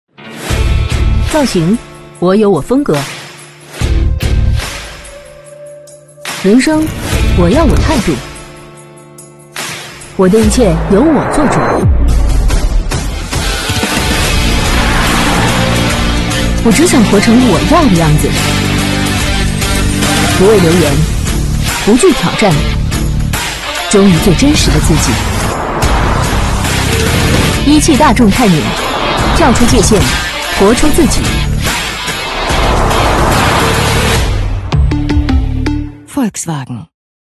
女35-汽车广告【大众一汽探影-时尚运动】
女35-磁性甜美 质感磁性
女35-汽车广告【大众一汽探影-时尚运动】.mp3